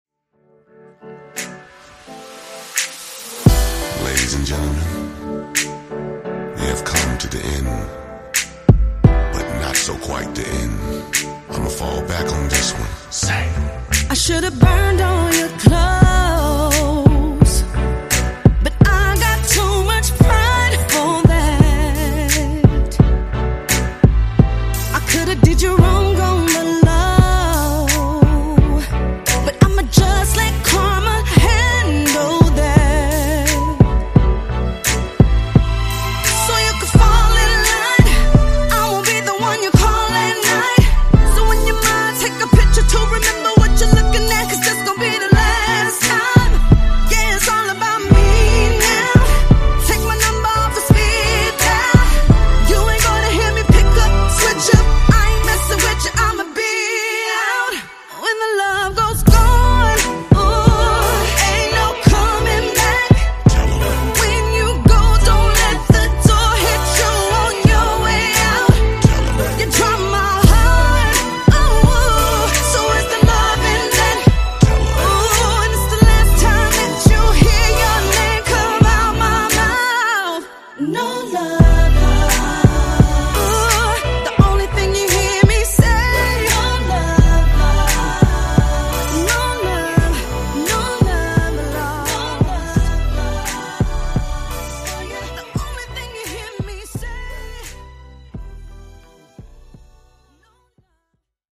Genre: RE-DRUM
Clean BPM: 120 Time